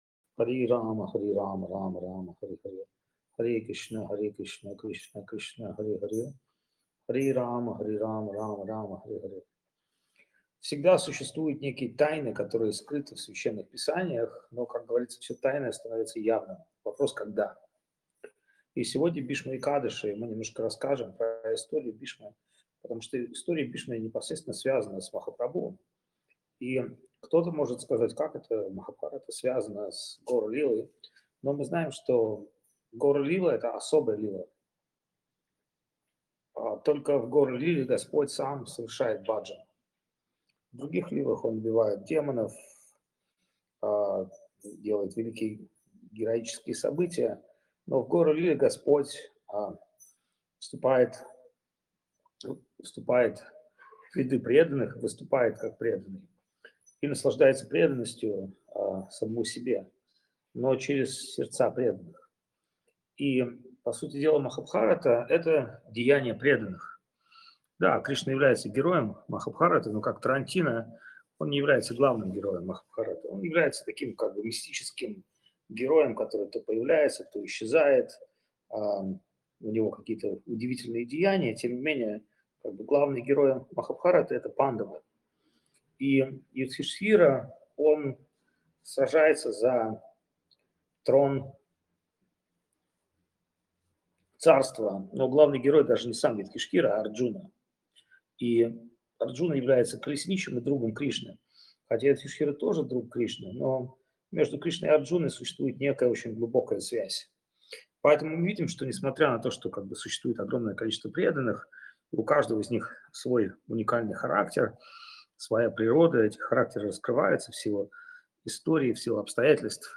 Индия
Лекции полностью